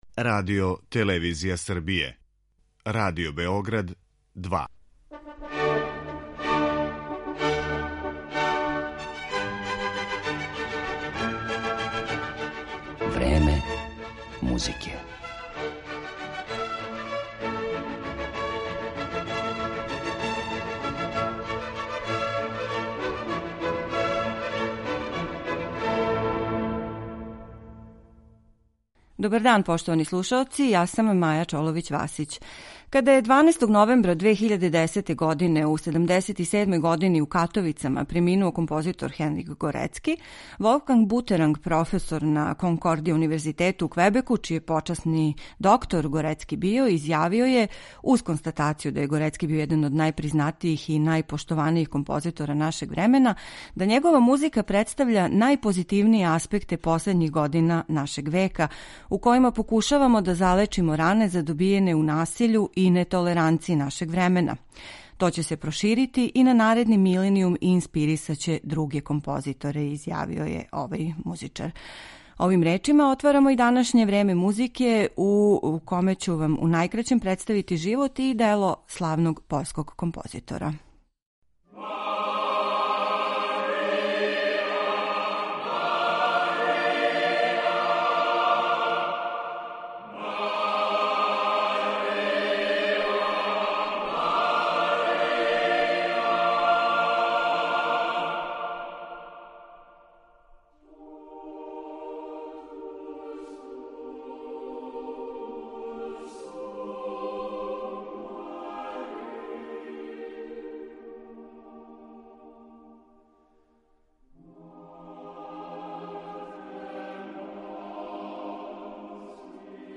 изабрала фрагменте Трећег гудачког квартета, Концерта за чембало, вокалних дела Totus Tuus и Mizerere , као и један став „Симфоније тужних песама"